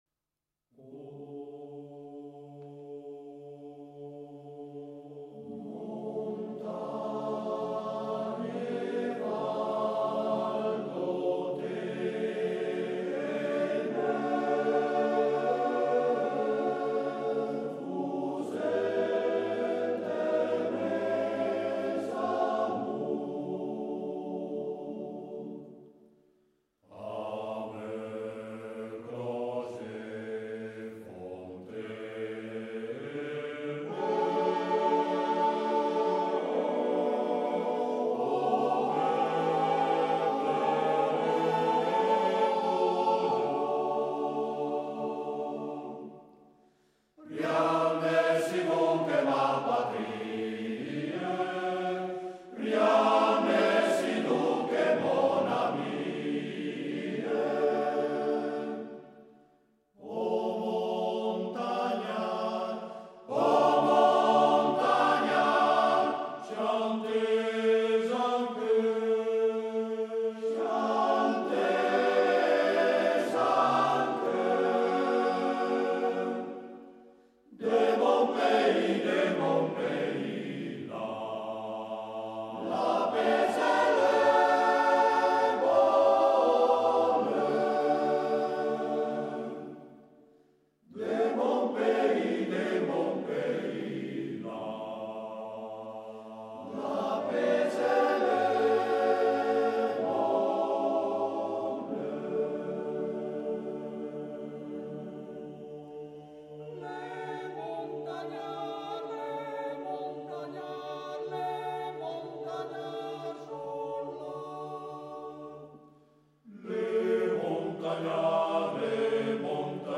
Scarica la canzone in MP3 cantata dal coro A.N.A. della città di Darfo Boario Terme